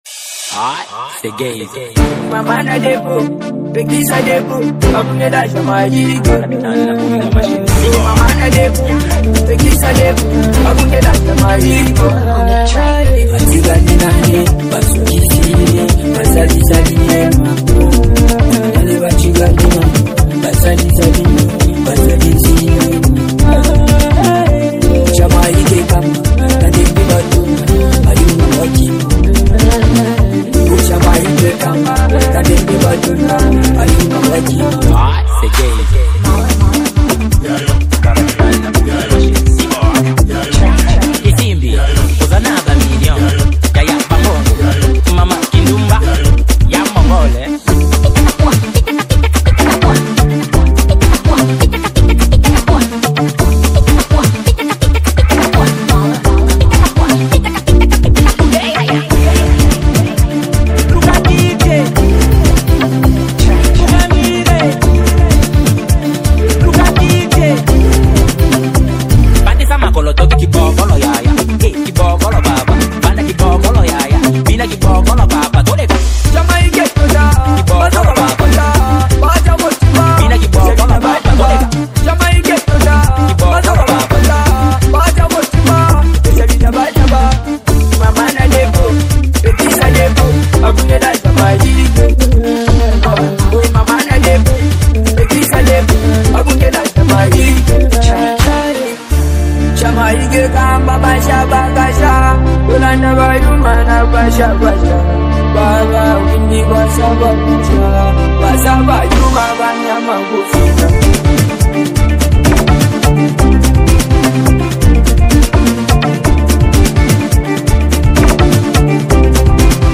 | Afro décalé